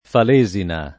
[ fal %@ ina ]